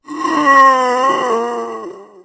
zombie_die_4.ogg